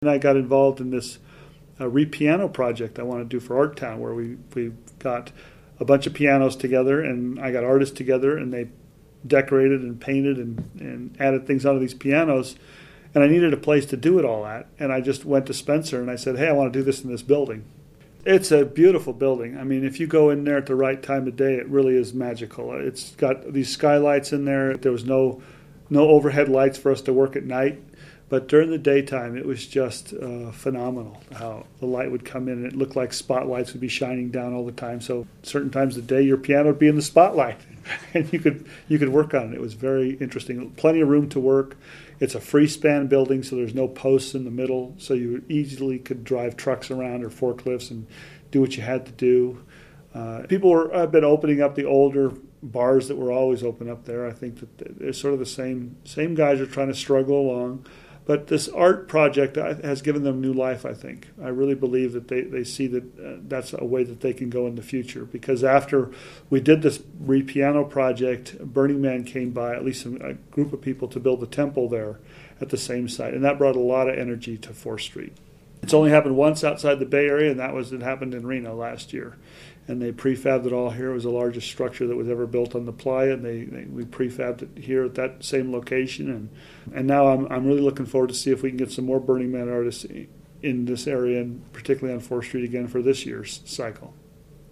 Interviewed in 2012, Dave Aiazzi, then a Reno City Councilman, recalls a recent collaboration